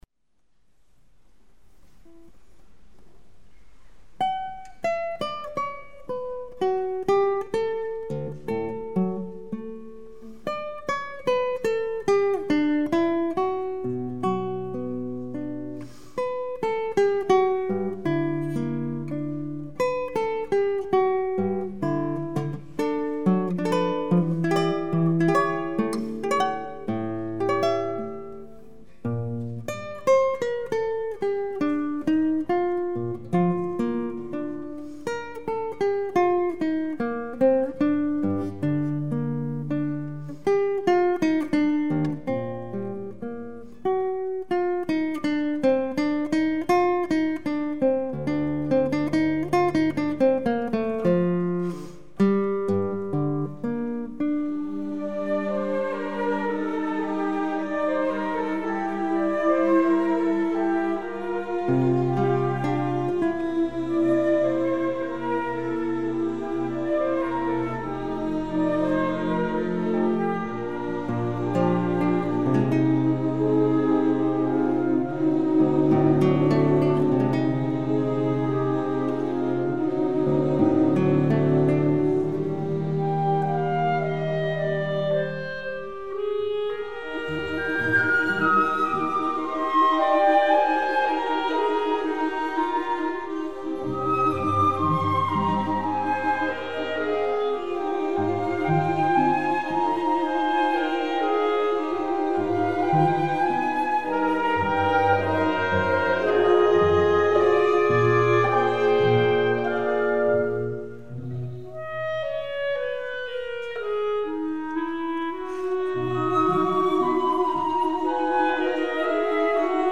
Hann samdi einnig mikinn fjölda annarra verka, þ.á.m. sjö óperur og yfir 300 verk fyrir gítar, þar á meðal tvo gítarkonserta og einn konsert fyrir tvo gítara.
Hér er á ferðinni annar þáttur af þremur úr gítarkonsert nr. 1, í D dúr, ópus 99 sem saminn var fyrri hluta árs 1939 fyrir Andrés Segovia. Þessi annar þáttur sem byggður er á stefjum ítalskra þjóðlaga, er saknaðarfull kveðja til fósturjarðarinnar.
Sinfóníuhljómsveit áhugamanna
Tónleikarnir voru haldnir 13. febrúar 2011 í Seltjarnarneskirkju
___________________________________ I am so lucky to be able to train my self recording a symphony orchestra several times a year.
Recorder: Korg MR1000 1bit/5,644Mhz